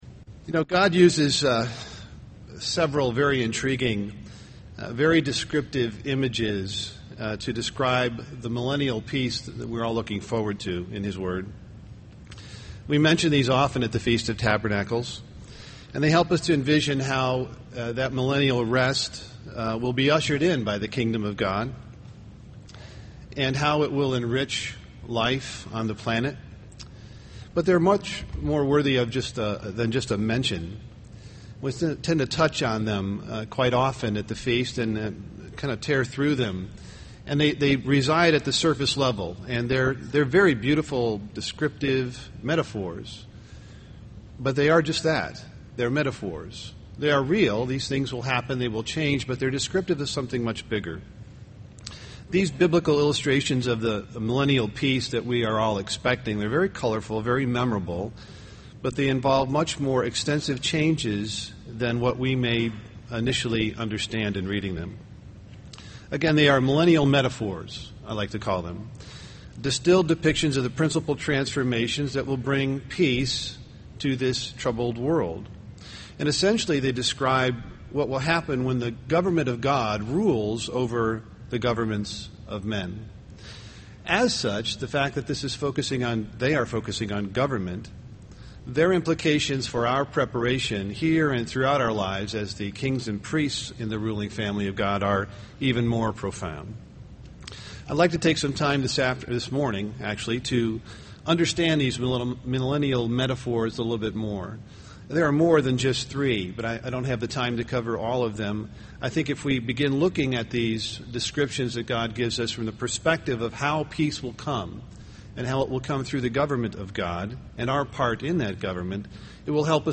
This sermon was given at the Wisconsin Dells, Wisconsin 2011 Feast site.